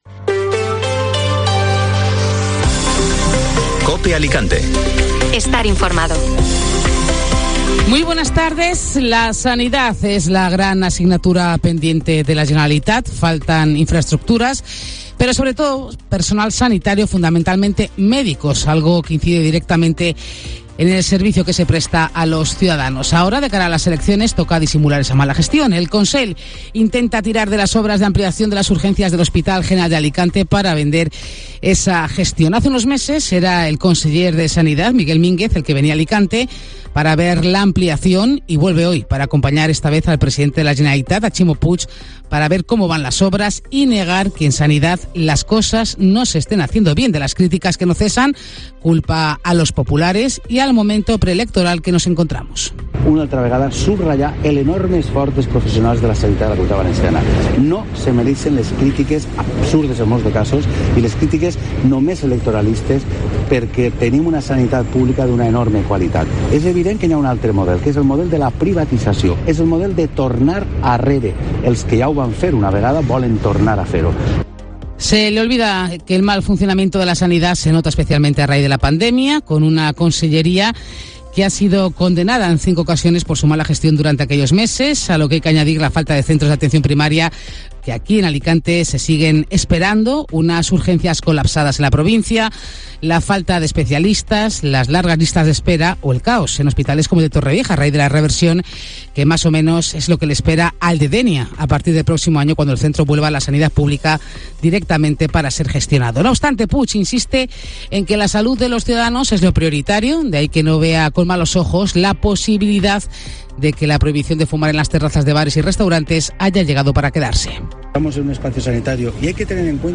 Informativo Mediodía COPE (Martes 7 de febrero)